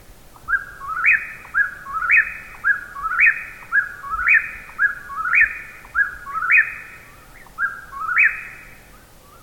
Eastern Whip-poor-will
(Antrostomus vociferous)
Eastern-Whip-poor-will-dit.mp3